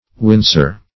wincer - definition of wincer - synonyms, pronunciation, spelling from Free Dictionary Search Result for " wincer" : The Collaborative International Dictionary of English v.0.48: Wincer \Win"cer\, n. One who, or that which, winces, shrinks, or kicks.